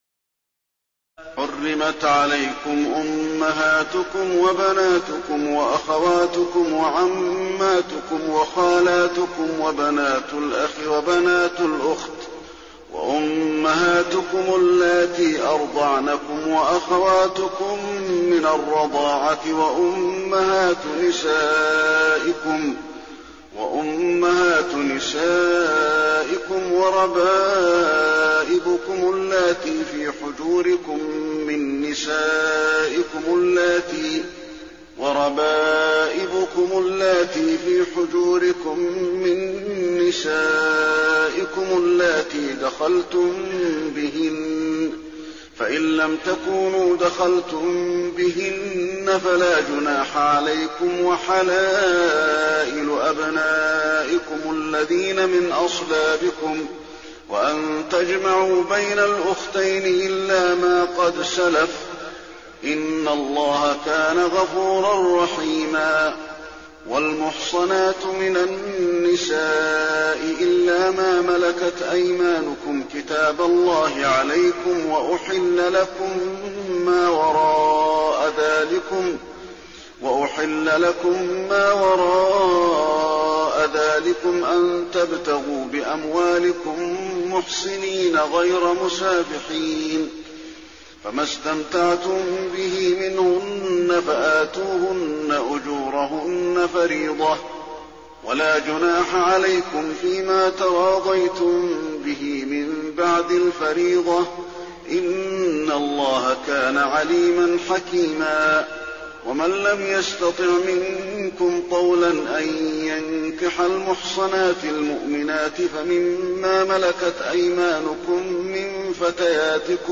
تراويح الليلة الخامسة رمضان 1422هـ من سورة النساء (23-87) Taraweeh 5 st night Ramadan 1422H from Surah An-Nisaa > تراويح الحرم النبوي عام 1422 🕌 > التراويح - تلاوات الحرمين